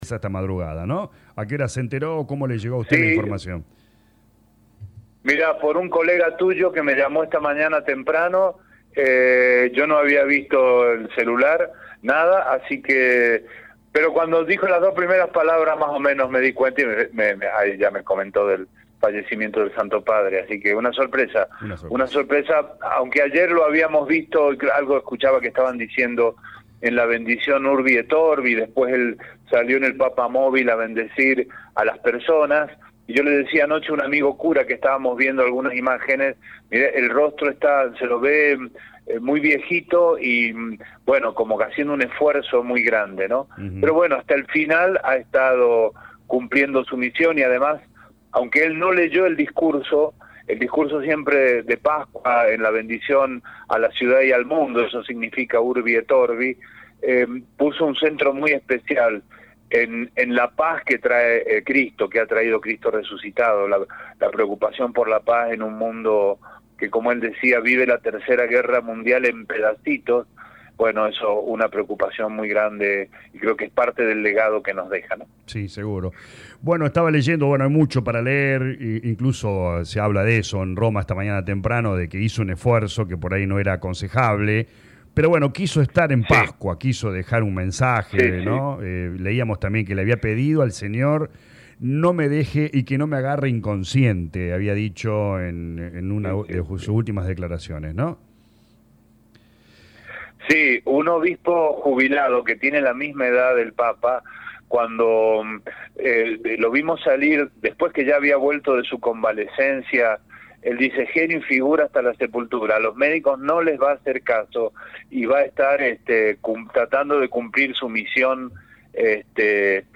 Desde el Obispado de San Francisco, Mons. Sergio Buenanueva habló en LA RADIO 102.9 de la triste noticia, cómo cambió la iglesia y su experiencia tras haberlo visitaado en enero en Roma.